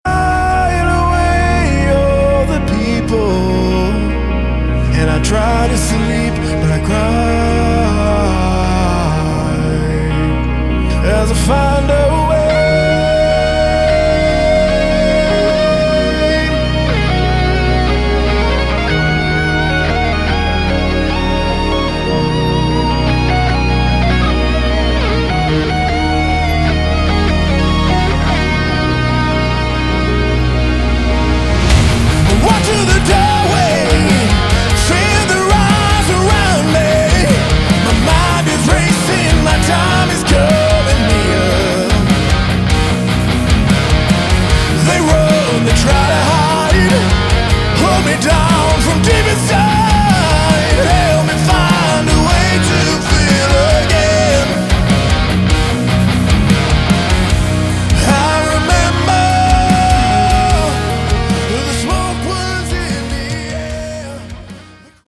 Category: Melodic Metal
vocals, guitars
bass
drums